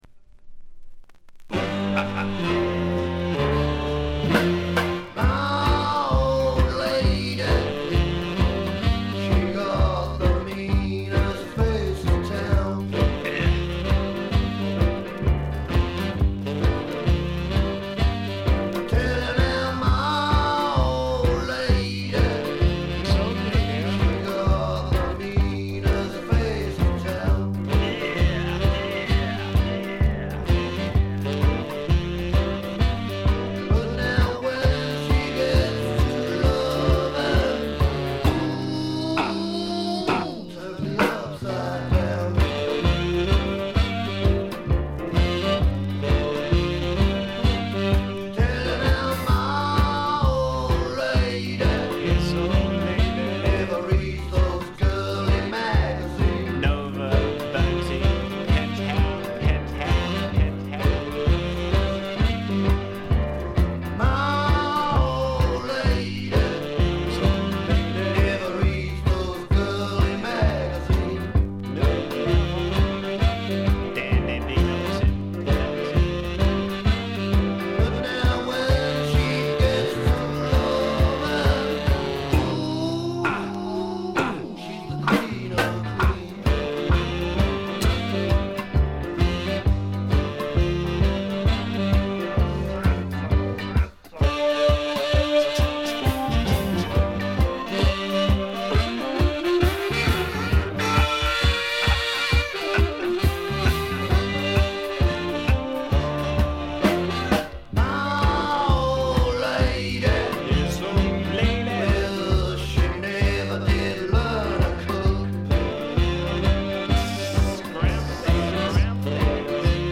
軽微なチリプチ少々、プツ音少々。
試聴曲は現品からの取り込み音源です。
Recorded At - Riverside Studios, London